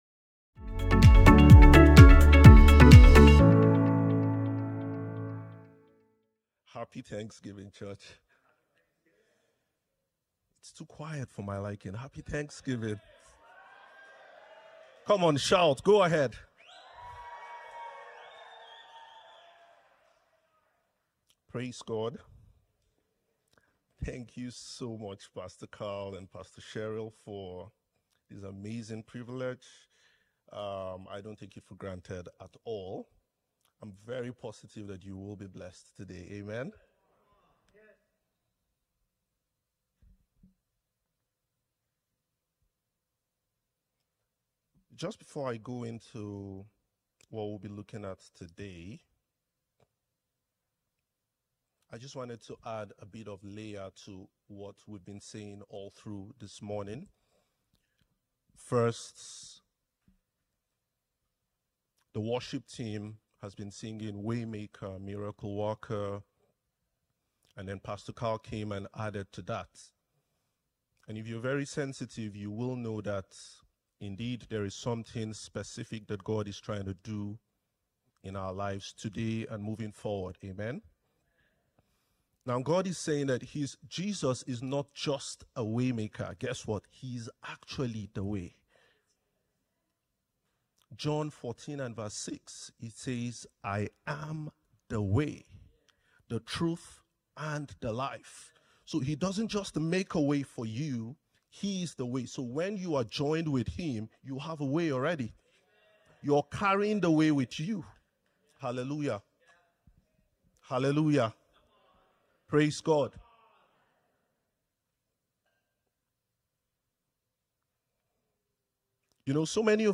THE UNSTOPPABLE GOSPEL | DRENCH SERIES | SERMON ONLY .mp3